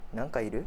Aizu Dialect Database
Type: Yes/no question
Final intonation: Rising
Location: Aizuwakamatsu/会津若松市
Sex: Male